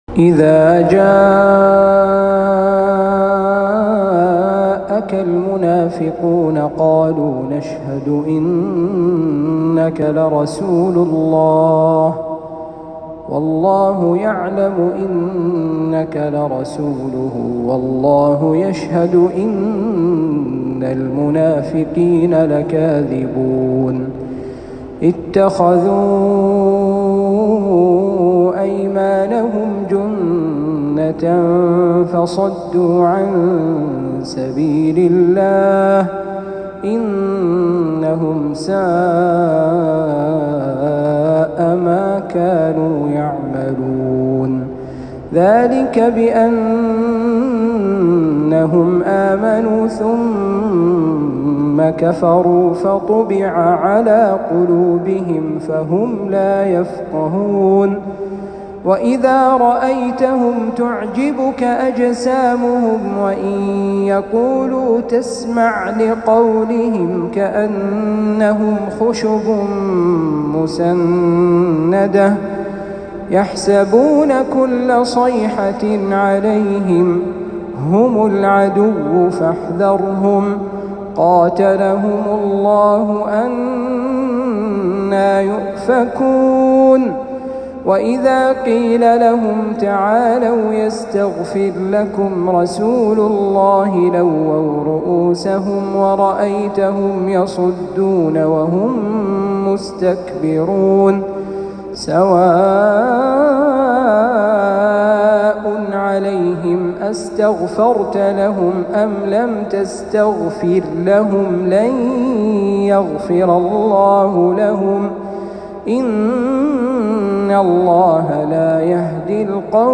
تلاوة لسورتي المنافقون والبروج